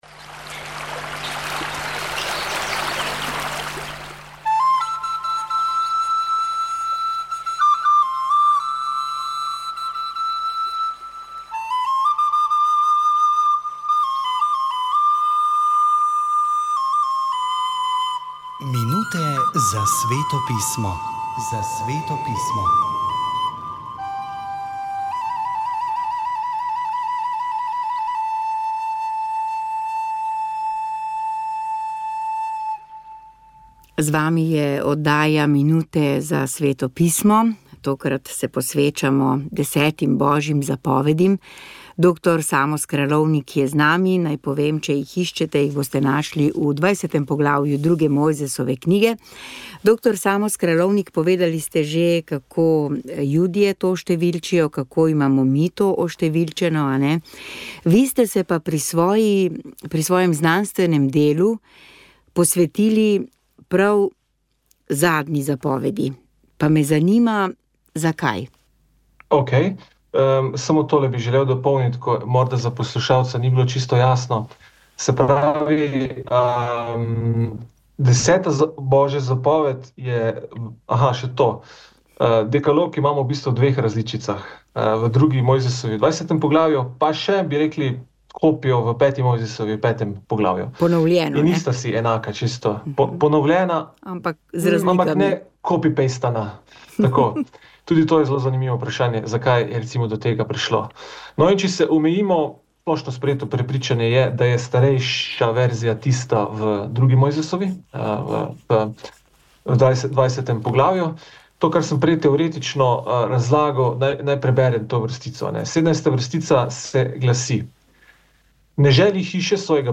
V Sobotnem duhovnem večeru ste najprej slišali duhovni nagovor za 28. nedeljo med letom, ki ga je pripravil škof Andrej Glavan. Sledile so prve nedeljske večernice in po njih molitev rožnega venca za mir v svetu. Po 21.uri je bila Radijska kateheza. Kristus kraljuje na križu - je naslov 53. govora sv. Leona Velikega, ki je živel med letoma 400 in 461.